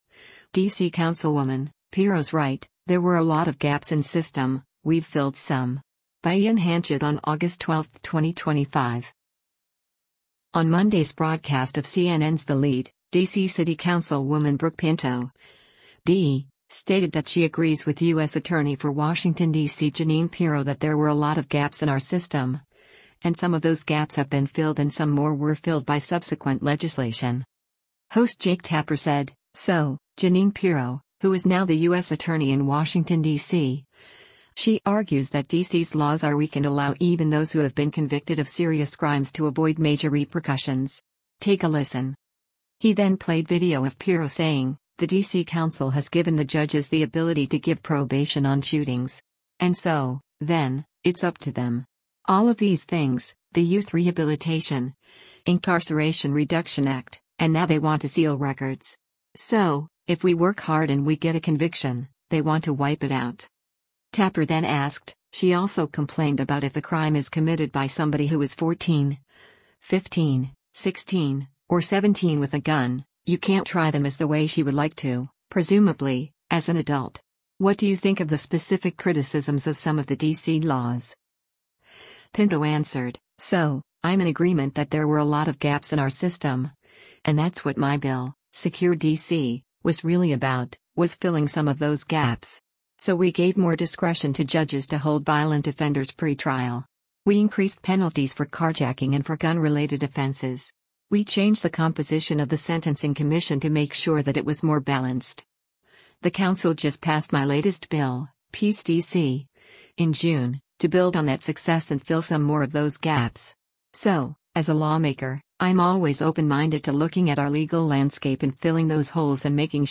On Monday’s broadcast of CNN’s “The Lead,” D.C. City Councilwoman Brooke Pinto (D) stated that she agrees with U.S. Attorney for Washington, D.C. Jeanine Pirro “that there were a lot of gaps in our system,” and “some” of those gaps have been filled and “some more” were filled by subsequent legislation.
He then played video of Pirro saying, “The D.C. Council has given the judges the ability to give probation on shootings.